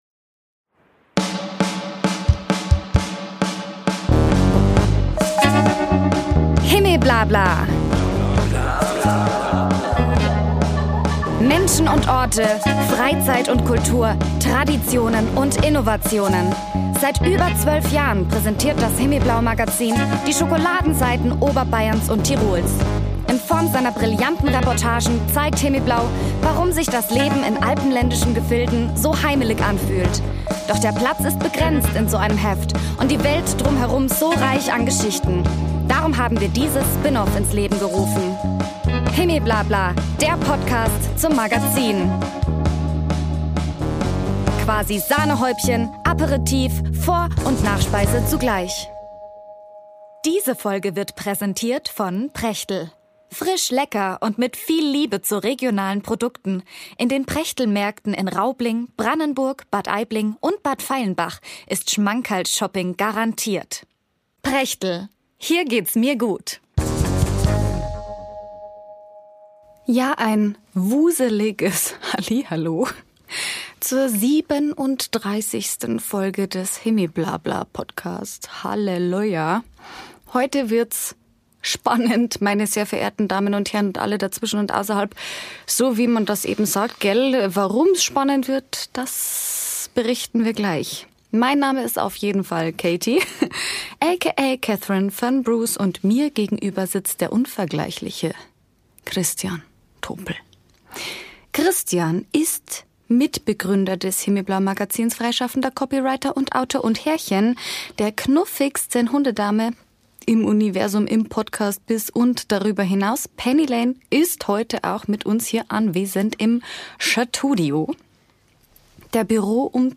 Folge 37 – Improvisationstheater im Schneegestöber.